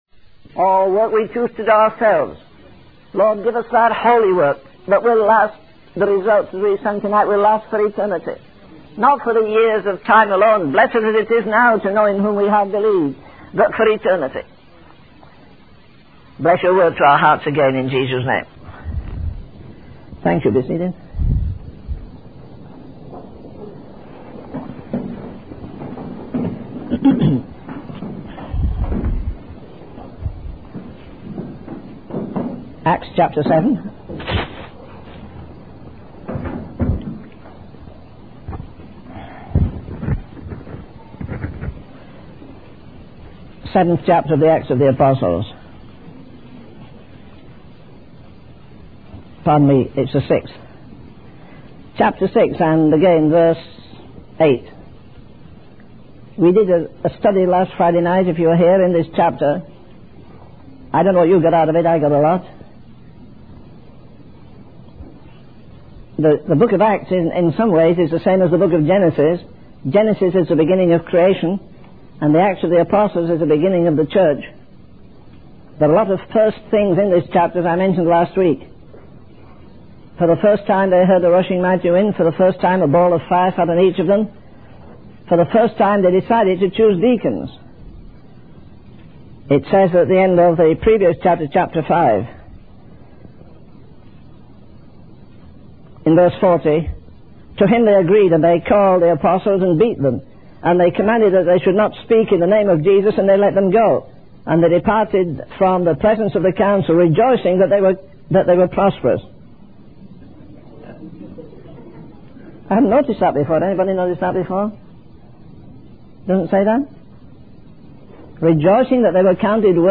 In this sermon, the speaker discusses the impending judgment of God and the challenges that believers will face in the future.